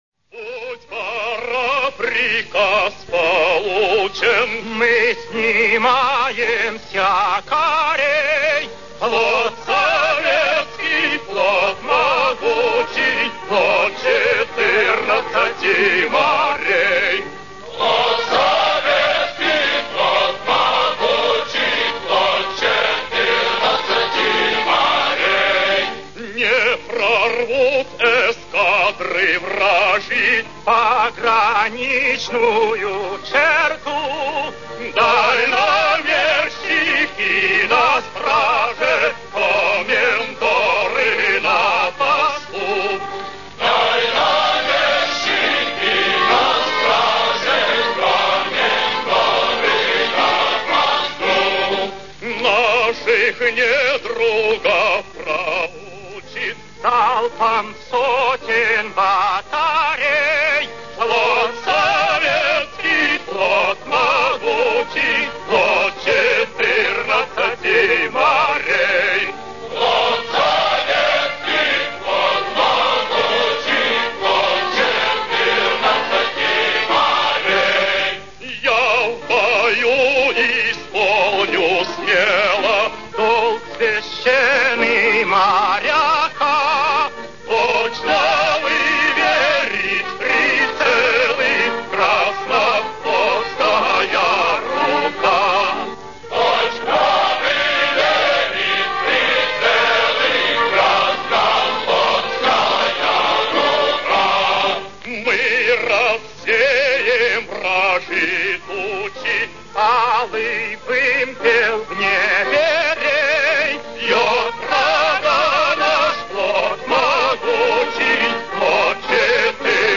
Морские песни